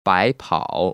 [báipăo] 바이파오  ▶